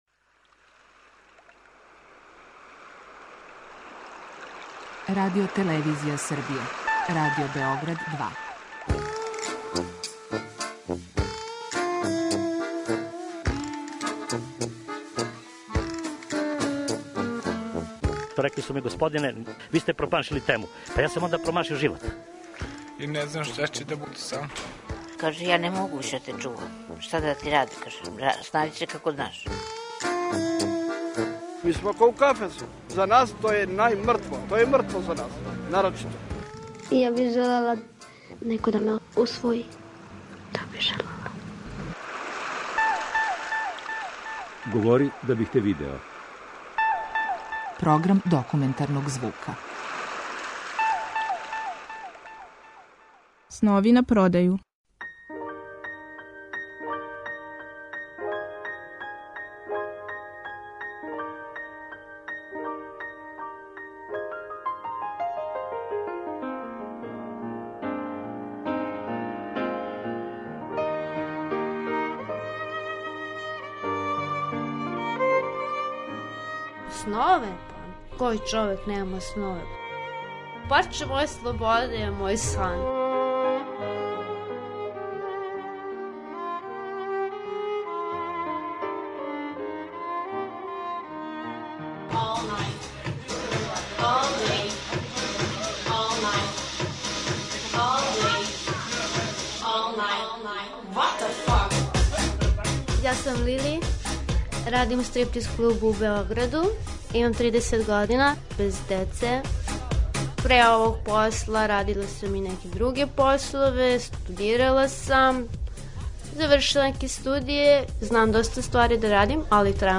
Документарни програм
Чућете репортажу Снови на продају , паралелне исказе две стриптизете различитих година, које говоре о природи свог посла, својим амбицијама и сновима.